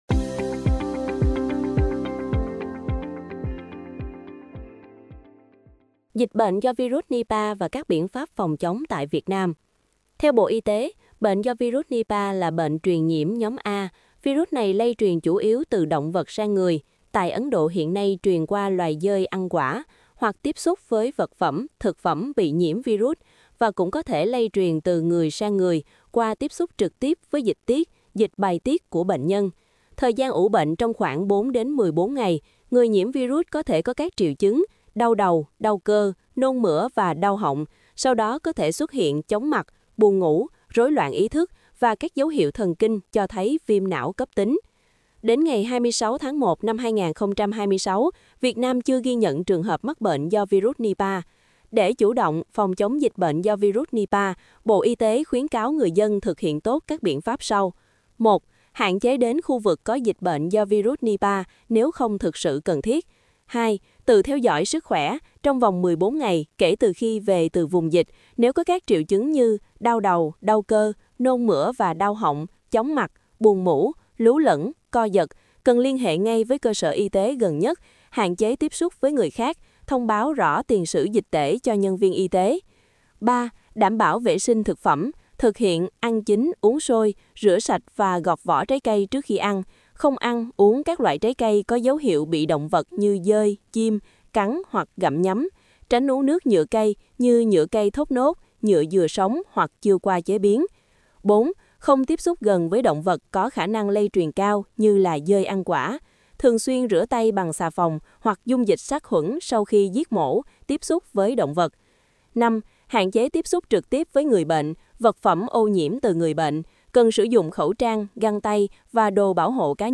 Bài phát thanh Dịch bệnh do vi rút Nipah và các biện pháp phòng chống tại Việt Nam